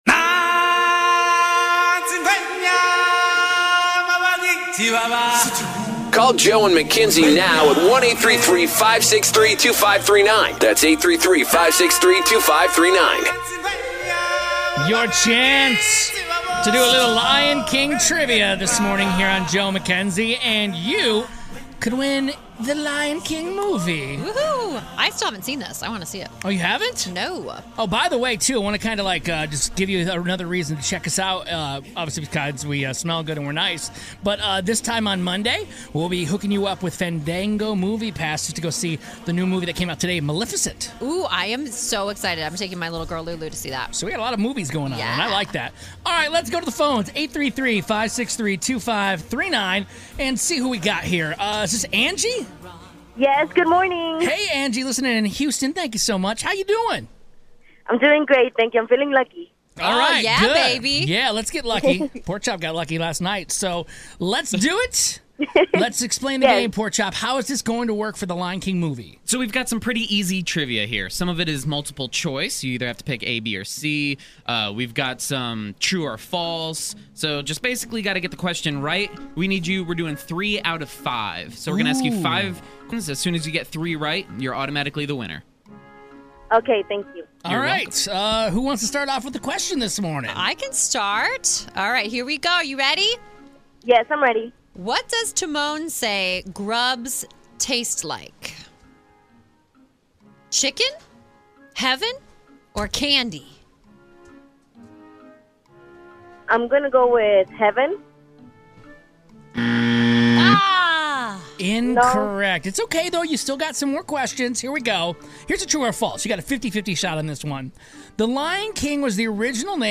We quiz some listeners on Lion King trivia for a chance to win a copy of the movie!